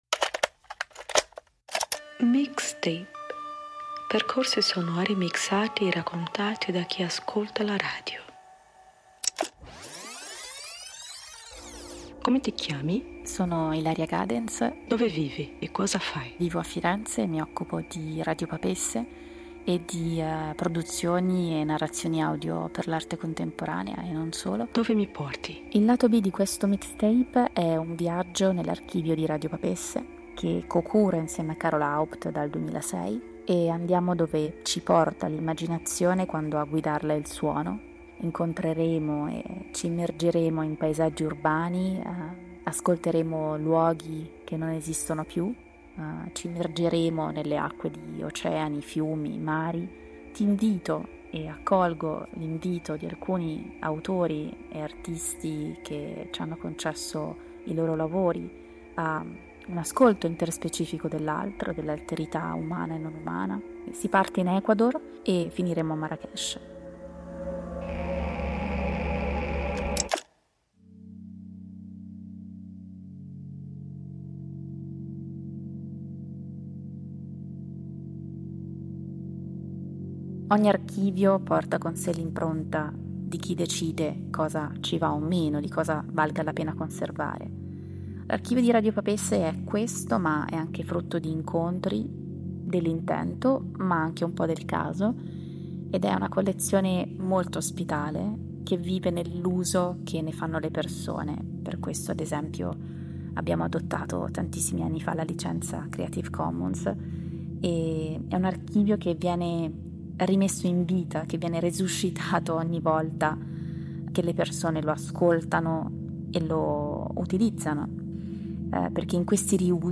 Percorsi sonori mixati e raccontati da chi ascolta la radio